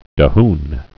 (də-hn)